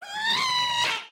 mob / ghast / scream2.ogg
scream2.ogg